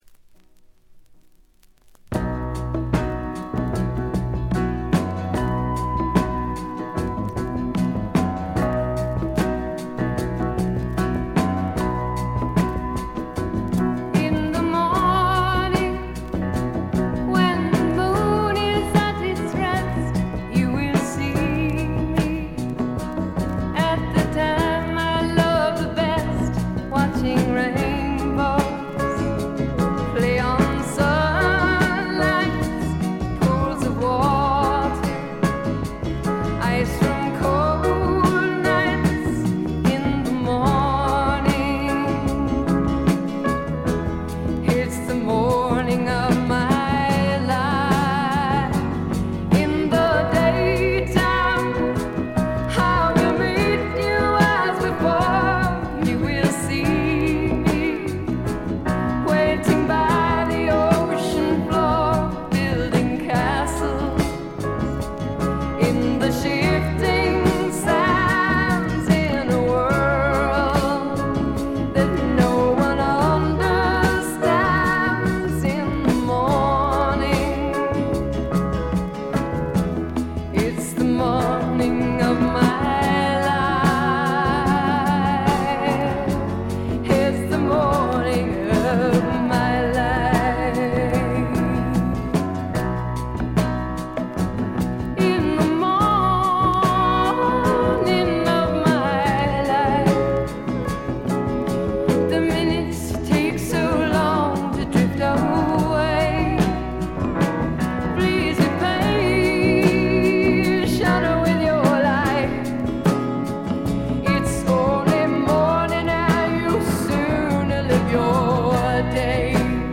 細かなチリプチ程度でまずまず良好に鑑賞できると思います。
試聴曲は現品からの取り込み音源です。
vocals